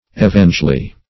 Search Result for " evangely" : The Collaborative International Dictionary of English v.0.48: Evangely \E*van"ge*ly\, n. Evangel.